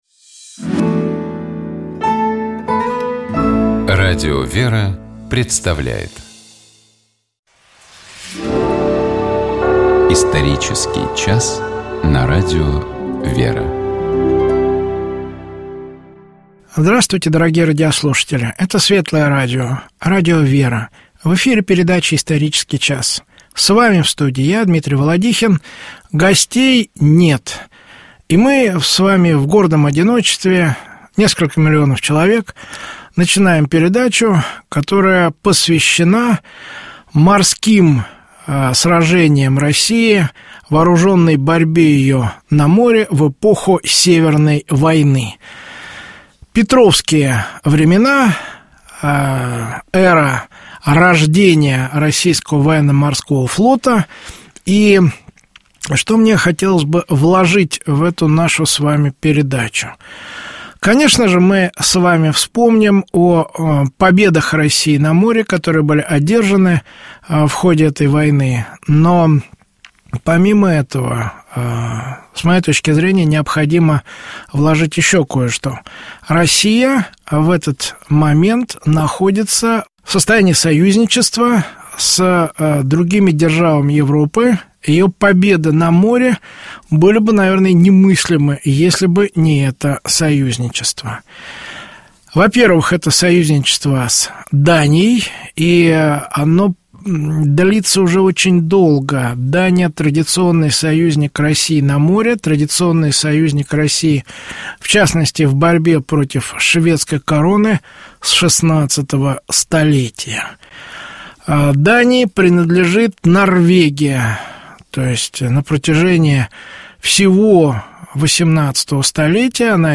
В этом выпуске своими светлыми воспоминаниями о том, как, казалось бы, далекий от праведности человек имел какую-либо добродетель, которая помогла ему измениться в лучшую сторону, поделились ведущие Радио ВЕРА